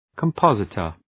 Προφορά
{kəm’pɒzıtər} (Ουσιαστικό) ● στοιχειοθέτης